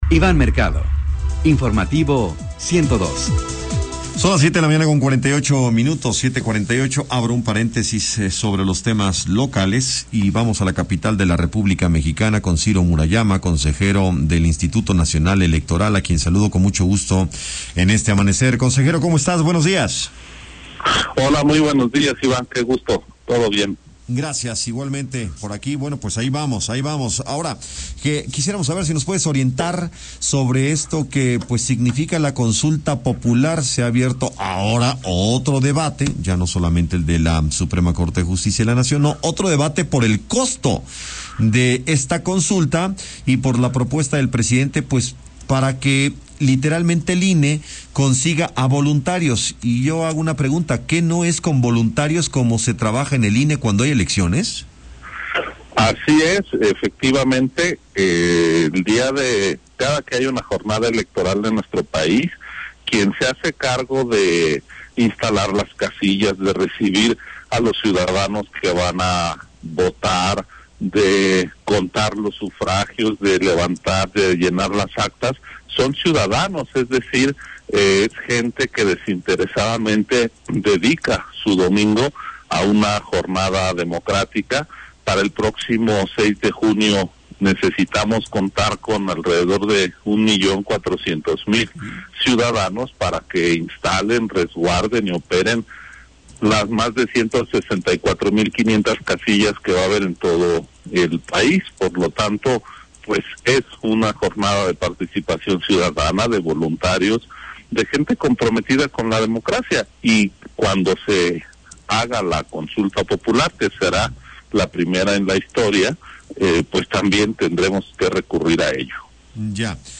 ENTREVISTA_CONSEJERO_CIRO_MURAYAMA_09_OCT_2020